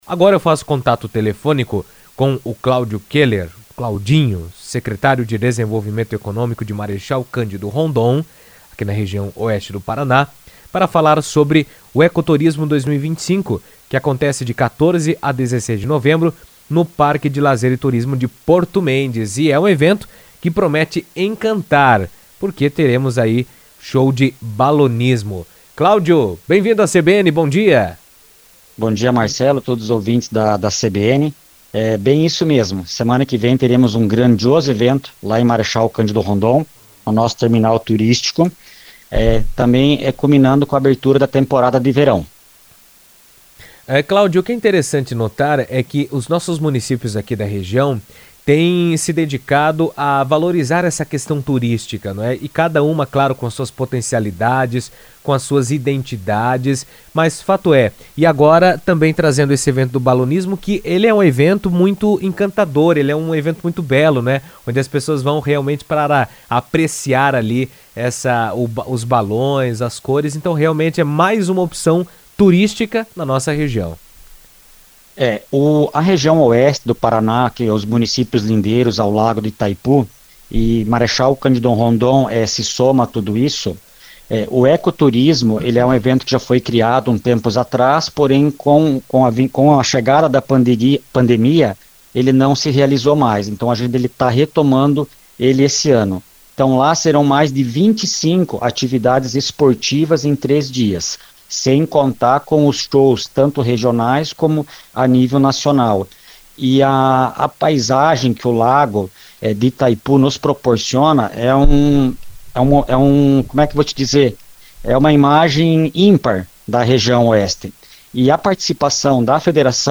O Ecoturismo 2025, promovido pela Prefeitura de Marechal Cândido Rondon, acontecerá nos dias 14, 15 e 16 de novembro em Porto Mendes, com uma atração especial nas alturas: o espetáculo de balonismo. Cláudio Kohler, secretário de Desenvolvimento Econômico de Marechal Cândido Rondon, falou sobre o evento na CBN, destacando que a programação promete encantar o público e fortalecer o turismo na região Oeste.